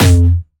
Jumpstyle Kick 3